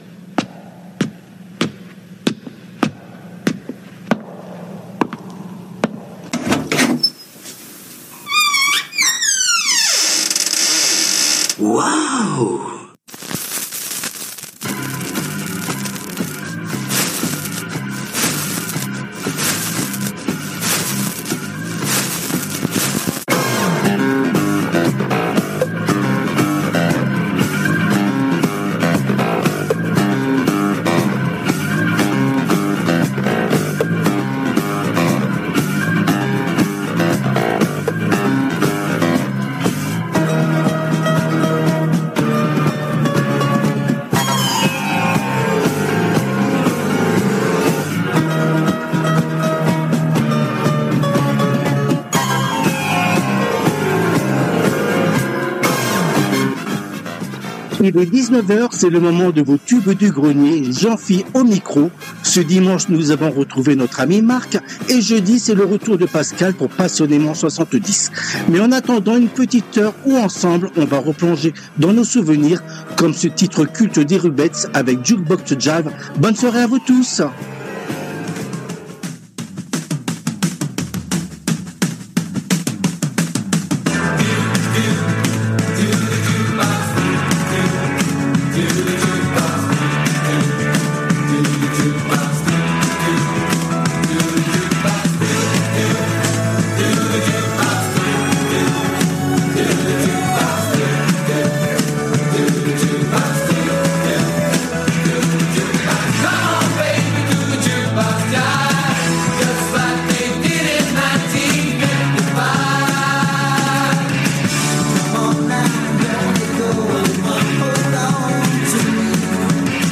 Cette 148ème édition a été diffusée en direct le mardi 24 février 2026 à 19h depuis les studios de RADIO RV+ à PARIS.